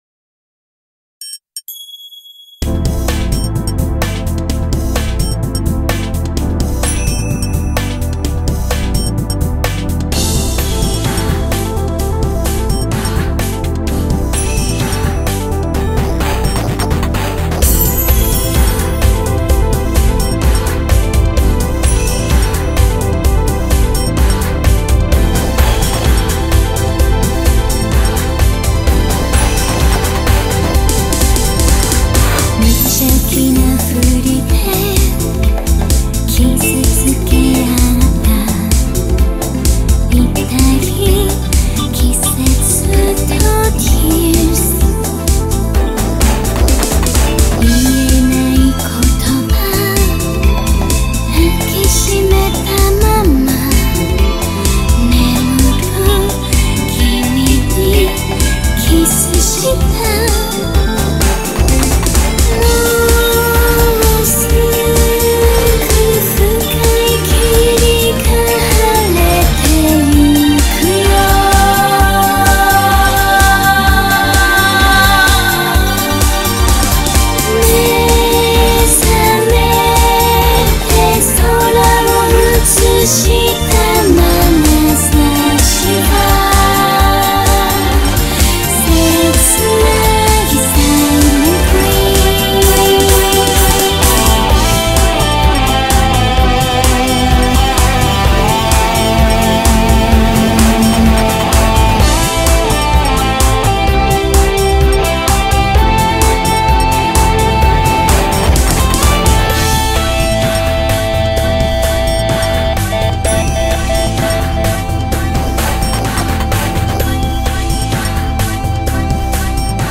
BPM128
Audio QualityPerfect (High Quality)